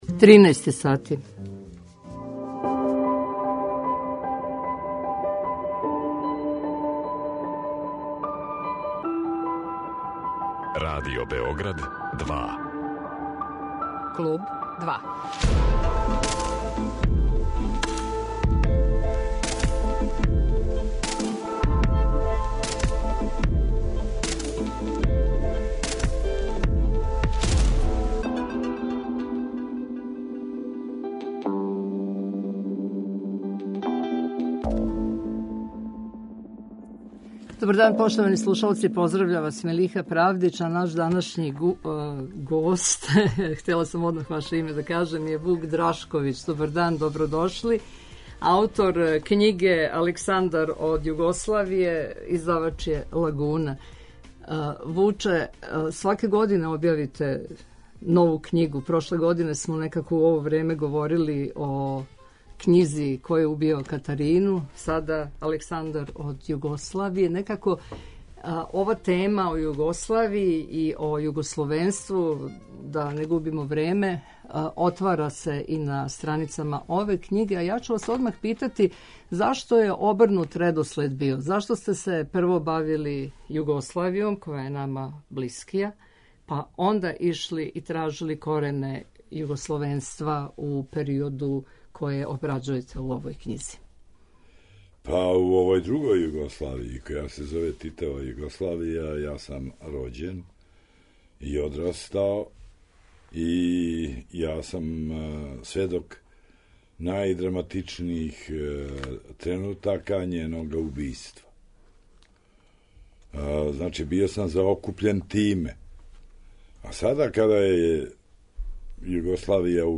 Гост 'Клуба 2' је књижевник Вук Драшковић, а говоримо о његовој књизи 'Александар од Југославије' (Лагуна)